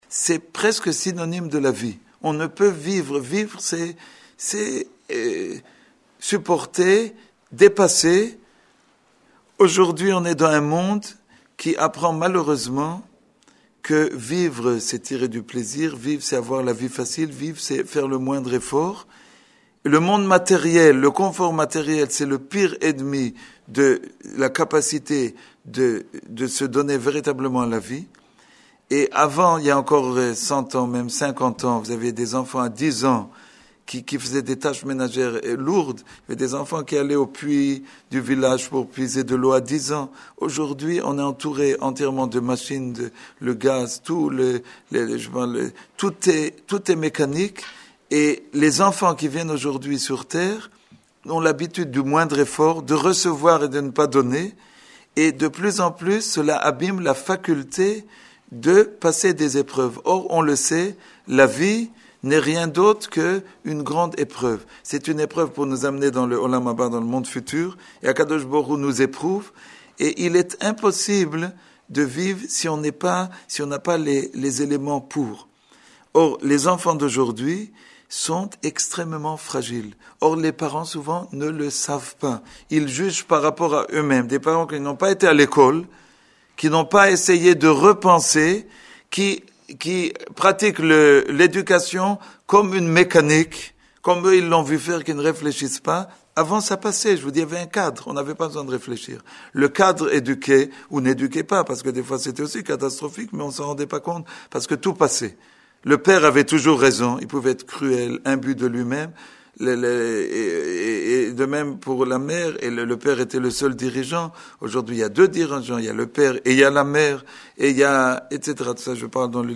01:45:53 Exposé donné à Tout Bishvath aux dames de la Communauté à l’école de la rue St Maur à Paris. Il nous parle de l’union et de l’unité dans le couple.